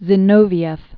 (zĭ-nōvē-ĕf, zyĭ-nôfyĭf), Grigori Evseyevich 1883-1936.